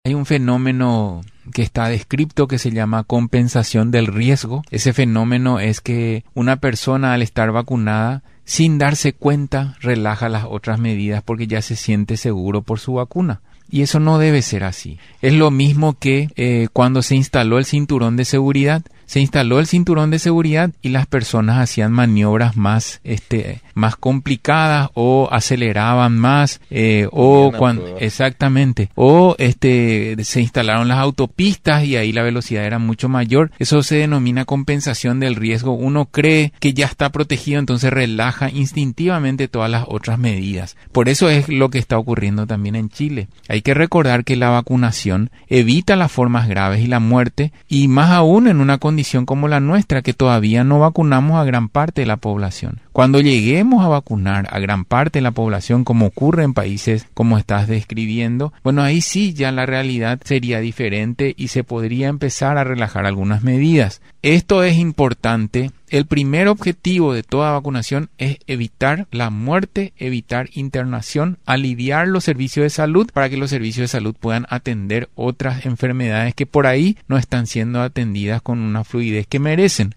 El Dr. Héctor Castro, Director del Programa Ampliado de Inmunizaciones, señaló en contacto con Radio Nacional del Paraguay que las personas que ya fueron vacunadas tienden a dejar de lado las medidas sanitarias contra recomendadas para evitar los contagios de COVID-19 creyendo que ya no se infectarán. Recordó que las vacunas evitan cuadros graves de la enfermedad, no así los contagios, y es un error que ha obligado países con una alta tasa de inmunización a regresar a las medidas restrictivas.